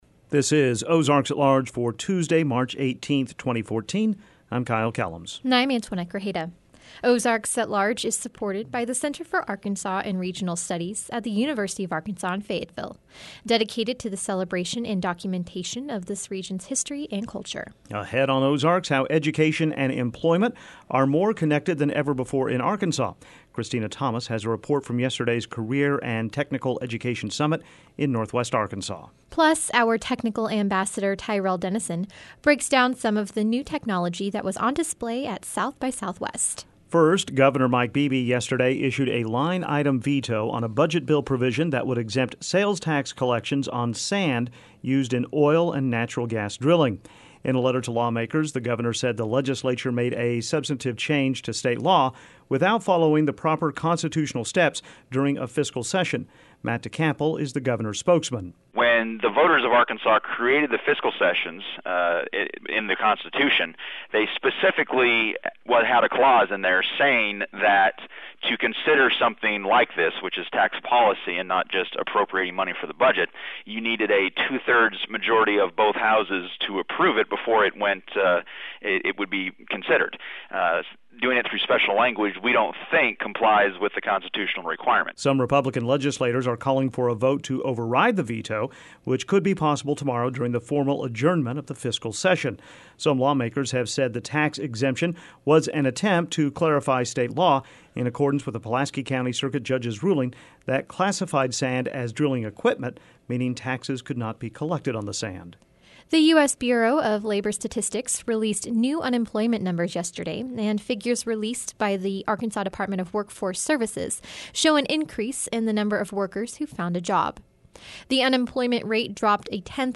We have a report on yesterday's Career and Technology Education summit in northwest Arkansas.